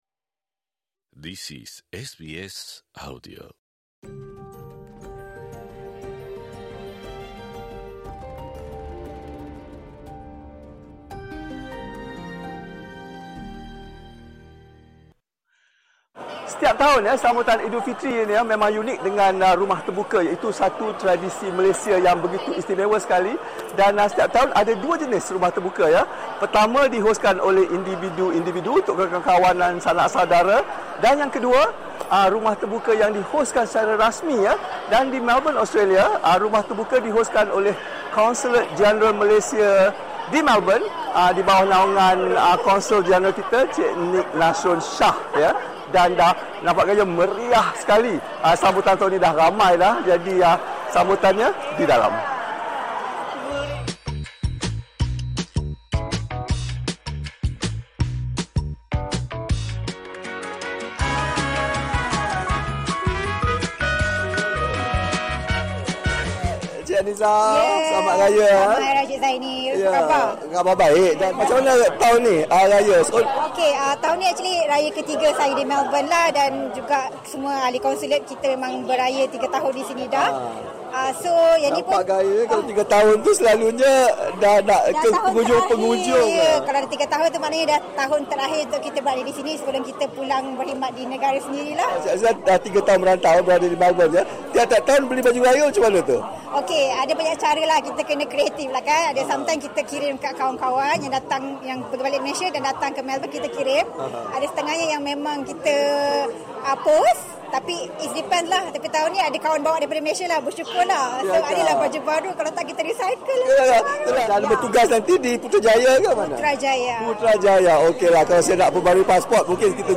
Bagi warga Malaysia, sambutan Hari Raya Eidul Fitri berterusan sepanjang bulan Syawal yang mulia. SBS Bahasa Melayu berpeluang menyertai sambutan Eidul Fitri anjuran Konsulat Jeneral Malaysia di Melbourne yang memaparkan perpaduan masyarakat berbilang kaum.